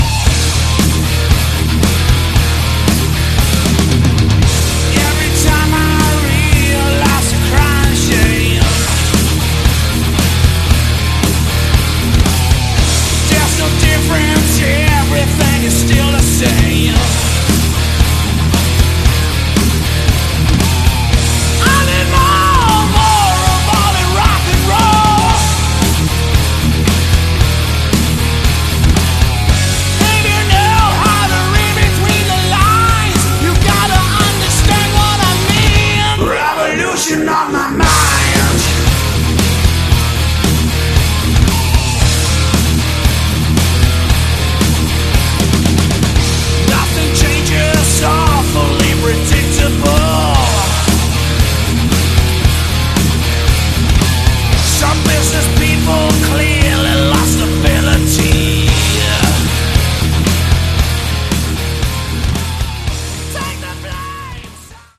Category: Hard Rock/ Melodic Metal
Excellent vocals and razor sharp guitars.